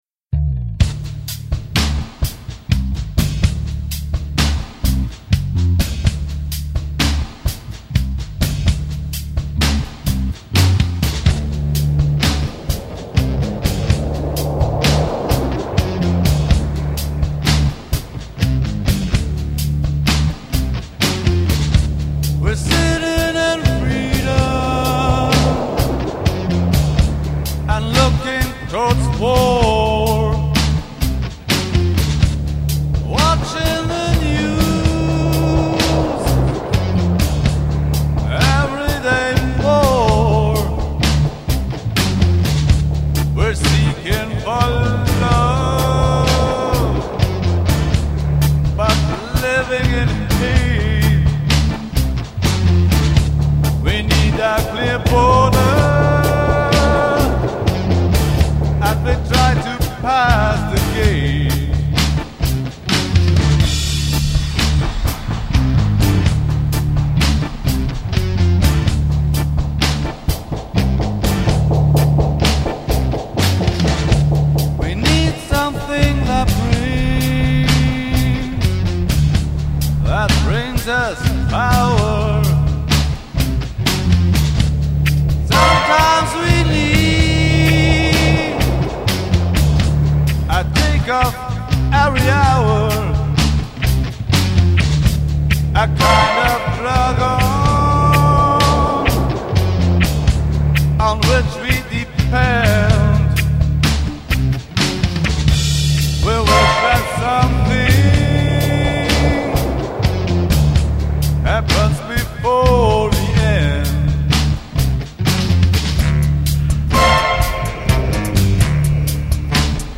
voice
guitars, samples, percussion, electronica
bass guitar
drums
bass clarinet (8)